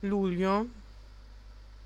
Ääntäminen
Synonyymit hömånad Ääntäminen Tuntematon aksentti: IPA: /ˈjʉːlɪ/ Lyhenteet ja supistumat jul Haettu sana löytyi näillä lähdekielillä: ruotsi Käännös Ääninäyte Substantiivit 1. luglio {m} Artikkeli: en .